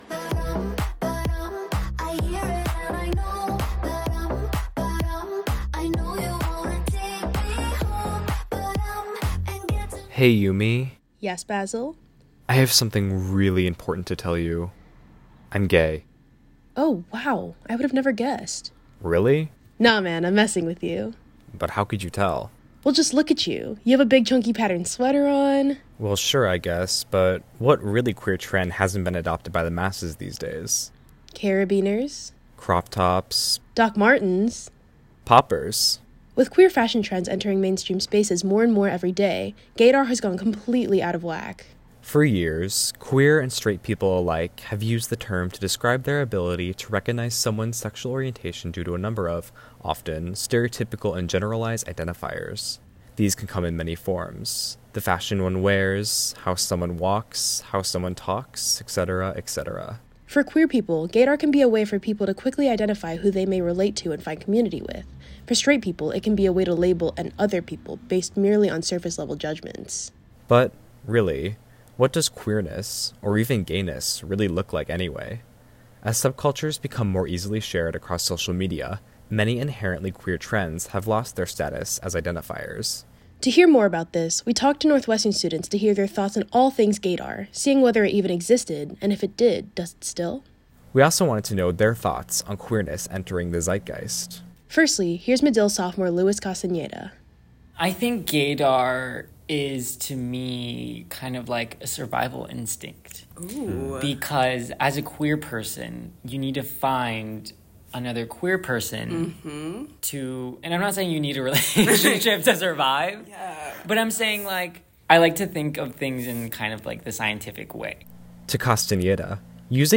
This story originally aired as part of our Homophone Special Broadcast.